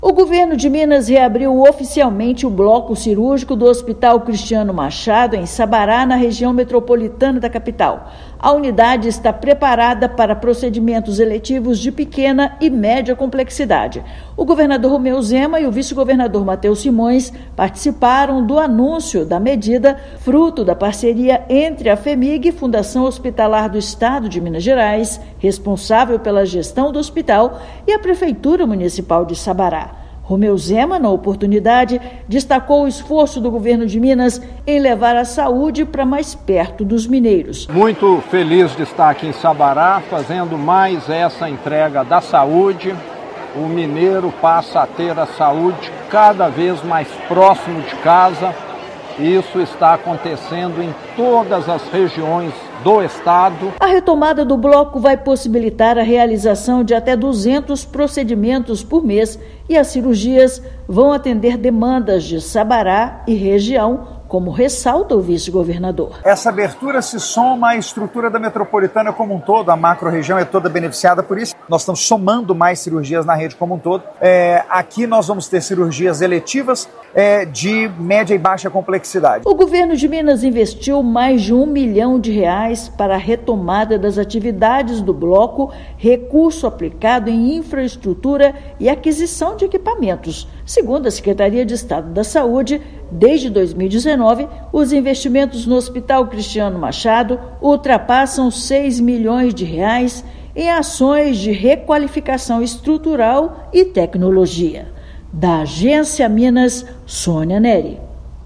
Cooperação entre Fhemig e Prefeitura permite cirurgias eletivas de pequeno e médio portes. Ouça matéria de rádio.